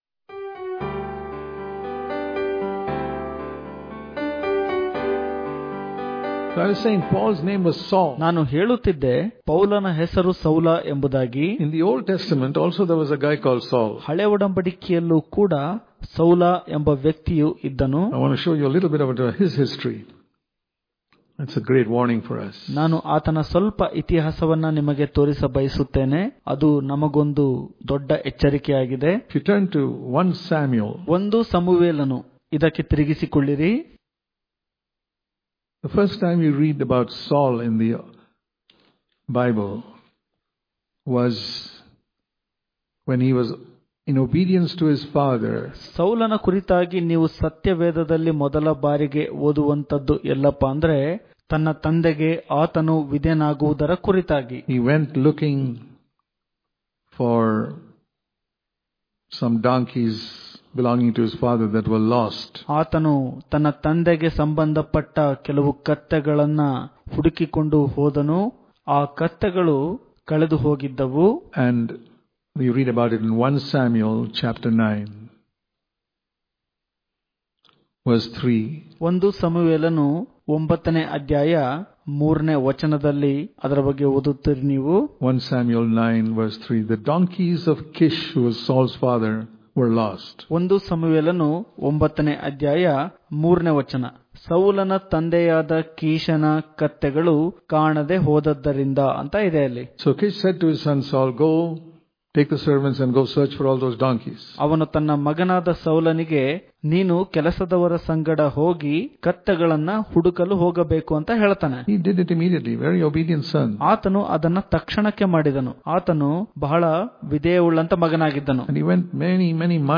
August 29 | Kannada Daily Devotion | Remain Small In Your Own Eyes If You Want To Win The Race Daily Devotions